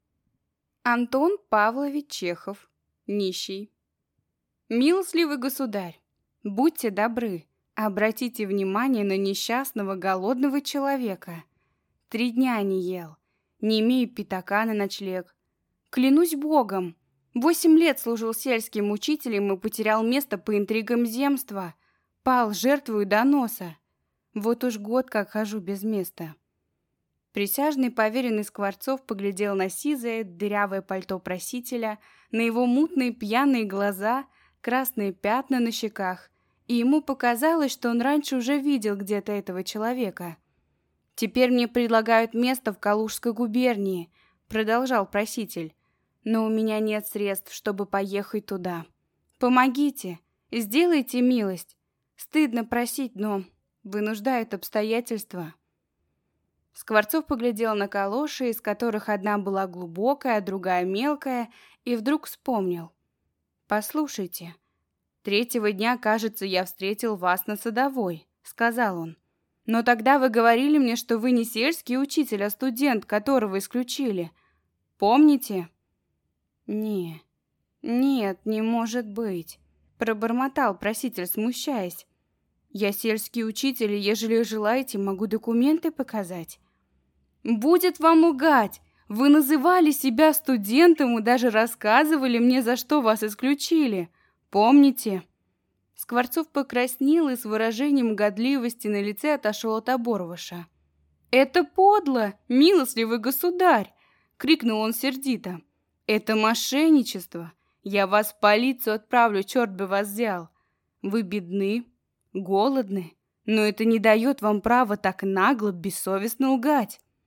Аудиокнига Нищий | Библиотека аудиокниг